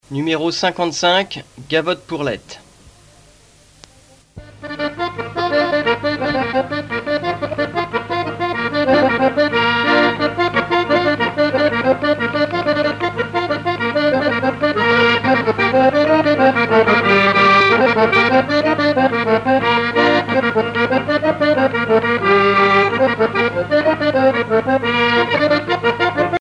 danse : gavotte bretonne
Pièce musicale éditée